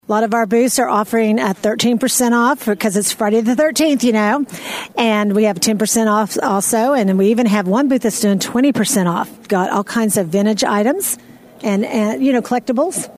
Bartlesville Radio was broadcasting live during the morning